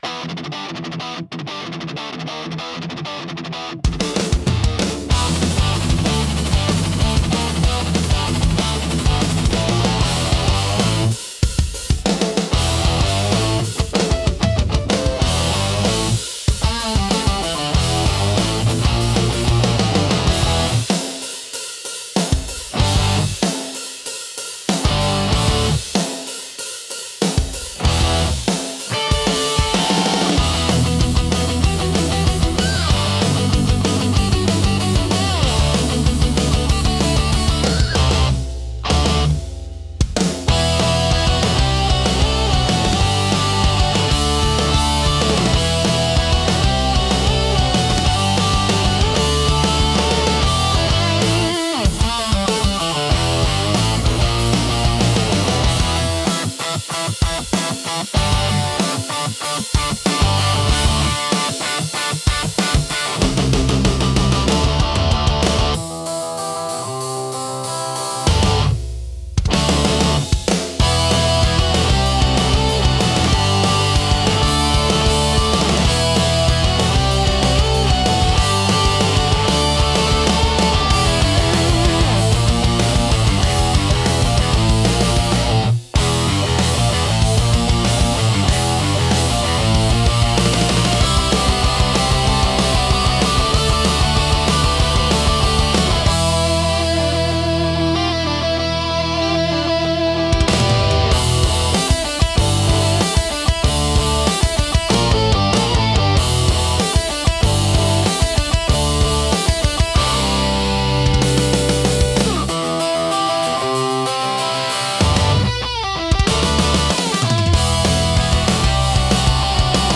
Gritty Skate Rock